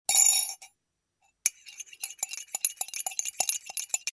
Звук ложки в бокале при размешивании